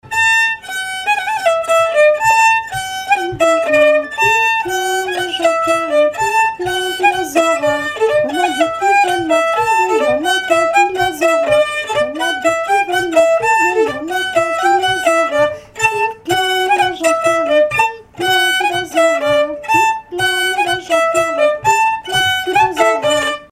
Couplets à danser
danse : polka piquée
Pièces instrumentales à plusieurs violons
Pièce musicale inédite